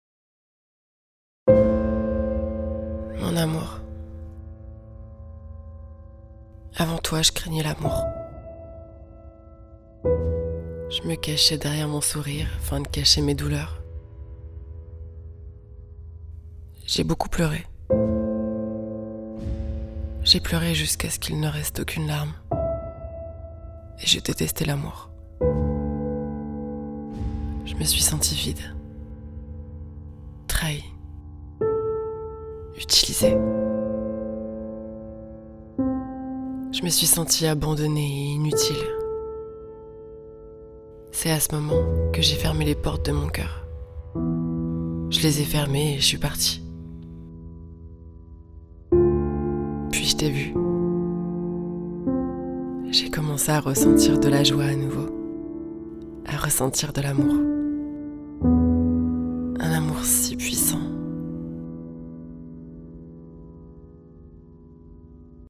Love letter narration
I have a medium deep voice with a large voice palette and many crazy characters at the service of your fairy tails, video games and commercials.